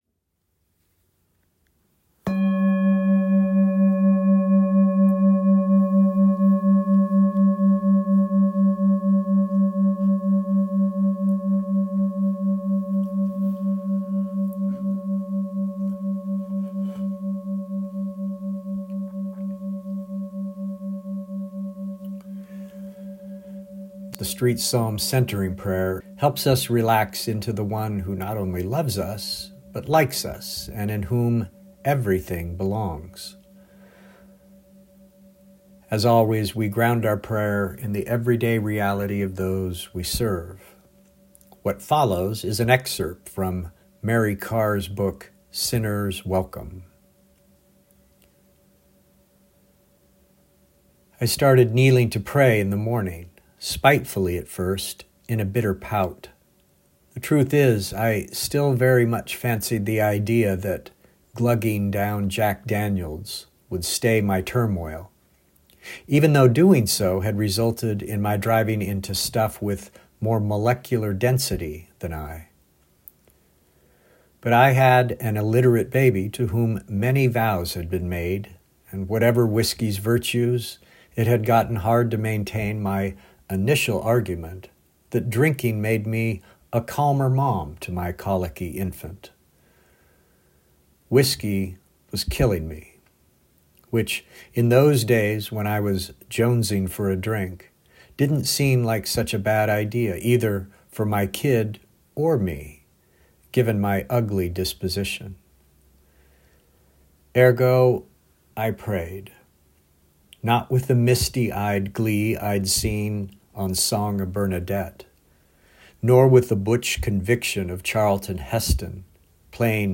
Prayer
This week's prayer is the Street Psalms Centering Prayer and includes a story from our global community:
Centering-Prayer-Full-Edit.mp3